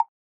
chad/client/public/sfx/message.ogg at master
message.ogg